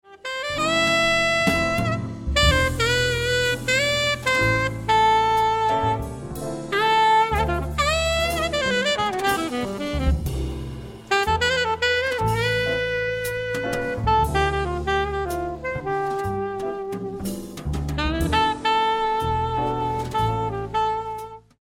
Recorded live at the Y Theatre Leicester November 2007